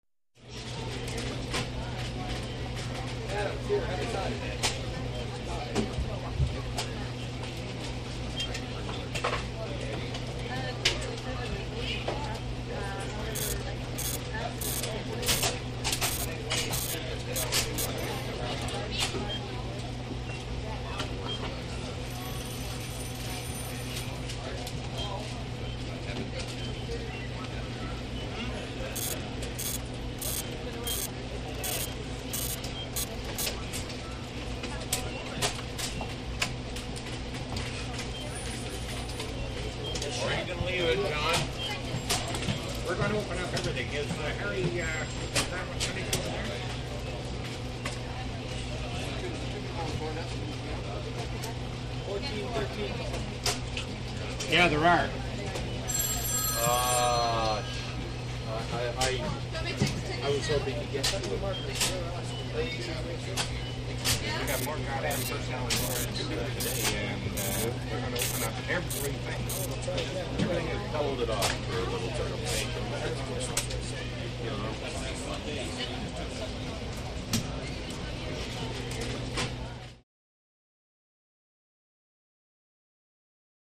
Control Center - Busy, Phones, Computers, Printers